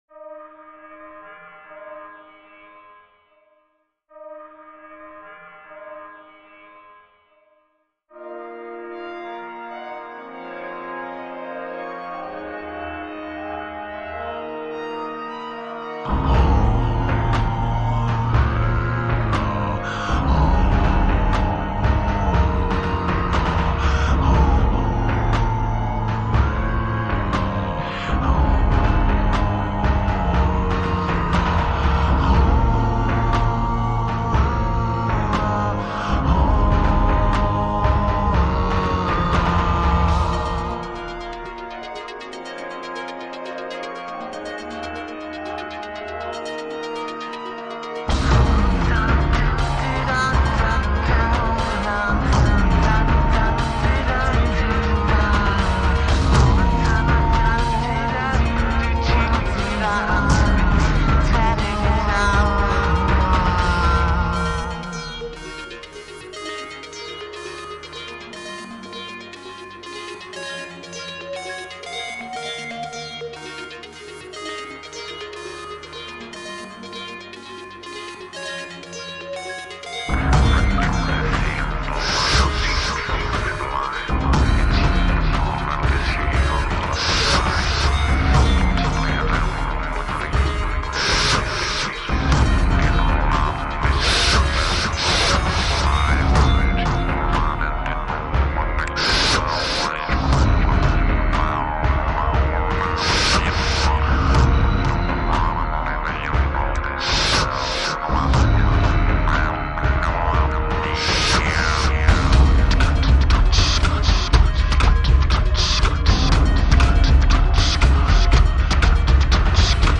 much more minimalistic and experimental